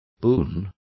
Complete with pronunciation of the translation of boons.